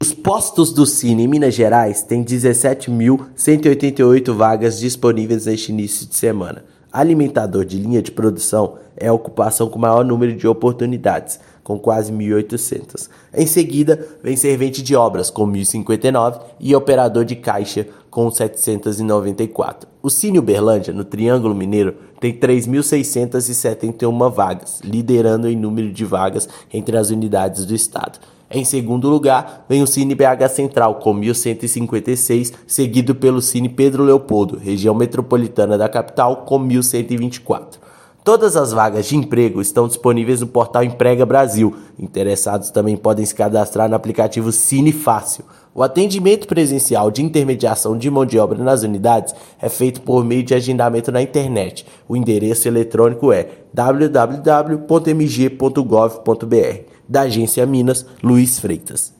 A unidade de Uberlândia, no Triângulo Mineiro lidera em número de vaga no estado. Ouça matéria de rádio.